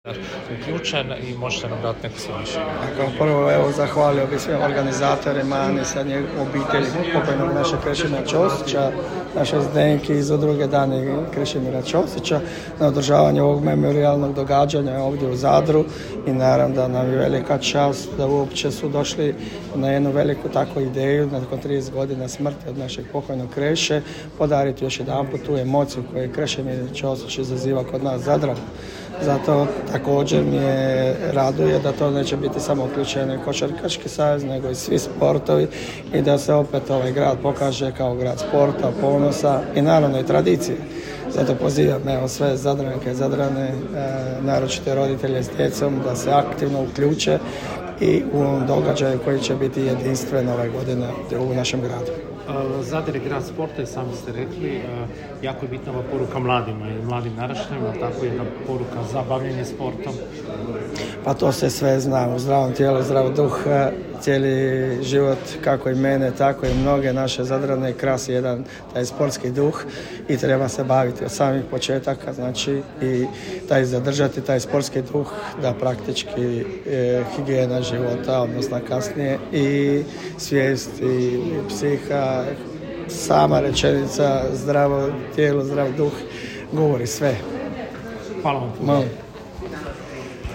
> Branko Dukić, gradonačelnik Grada Zadra